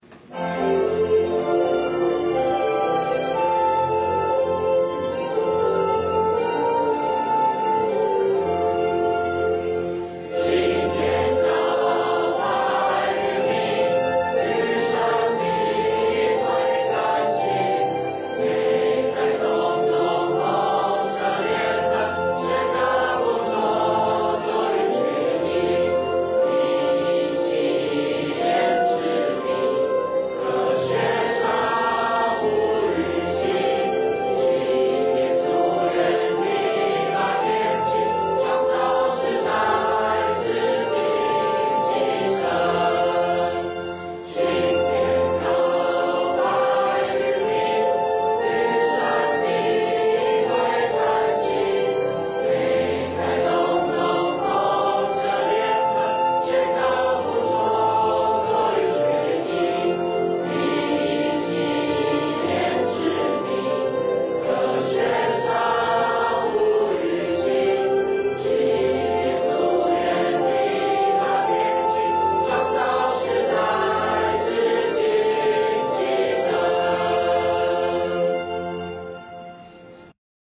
東石高中校歌(人聲).mp3